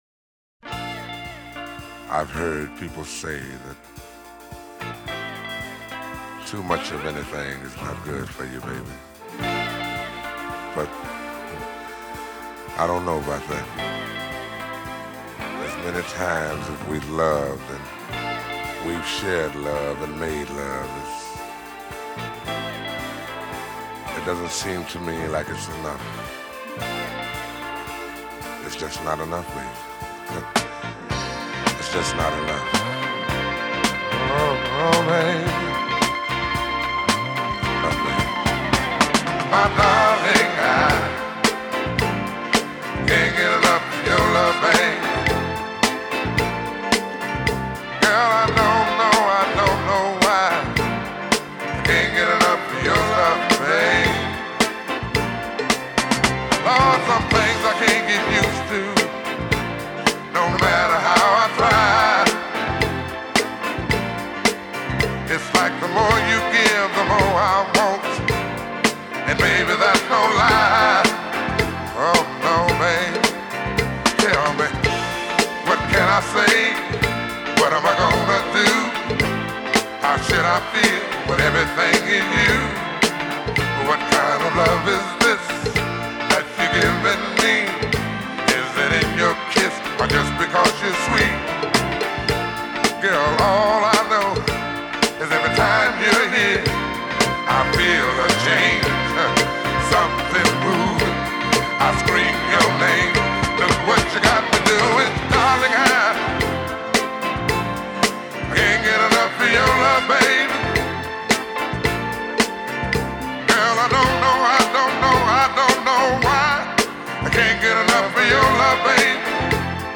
глубокий баритон и откровенно соблазнительная лирика.